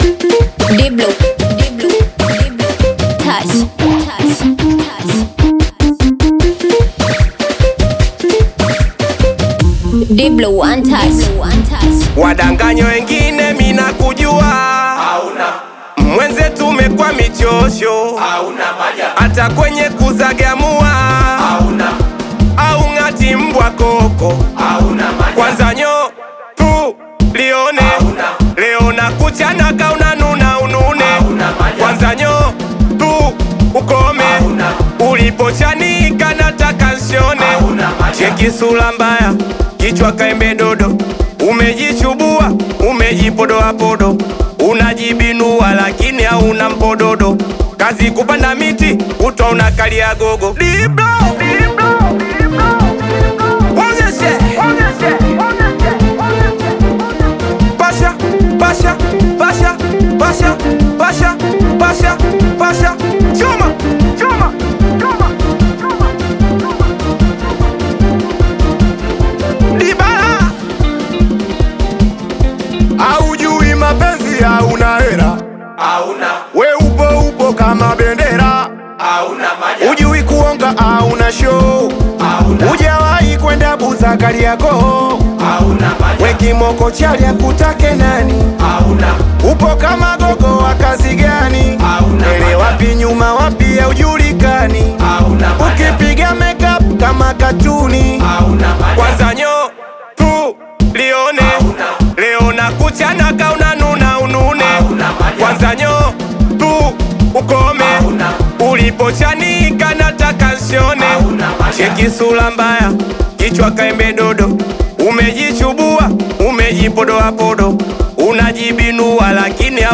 Singeli music track
Tanzanian Bongo Flava